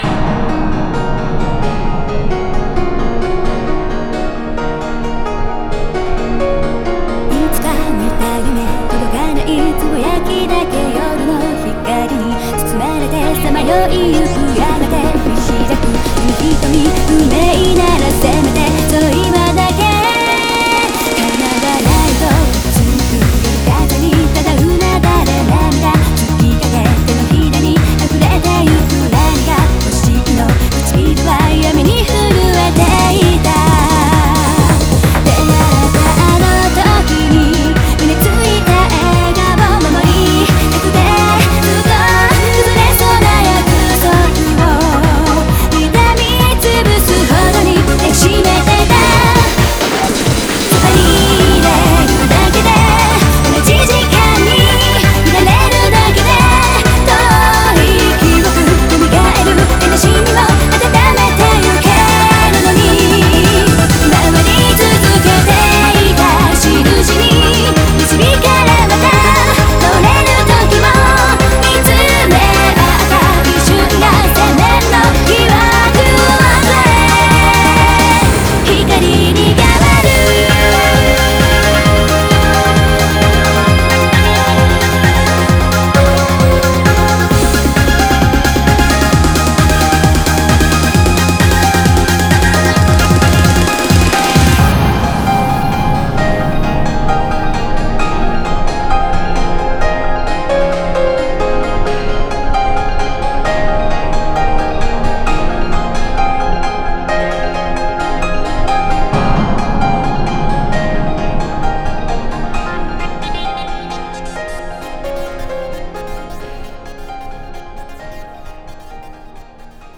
BPM131
Audio QualityPerfect (High Quality)